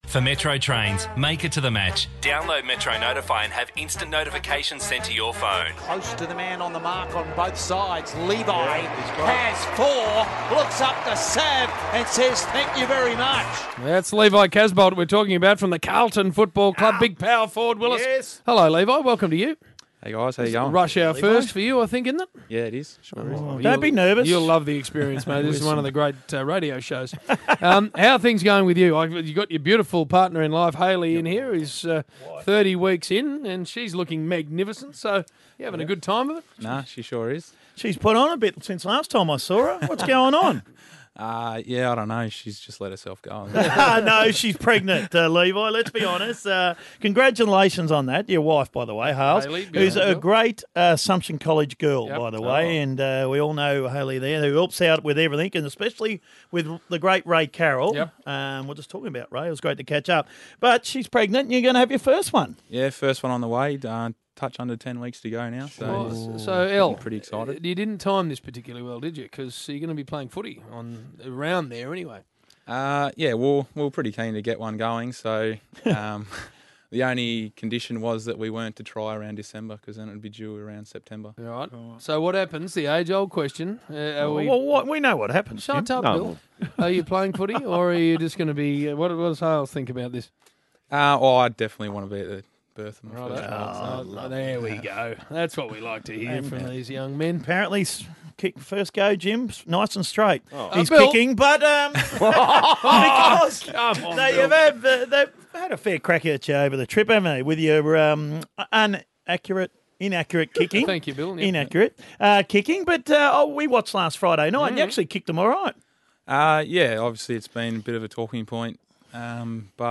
Carlton forward Levi Casboult joins the Triple M Rush Hour boys in the studio.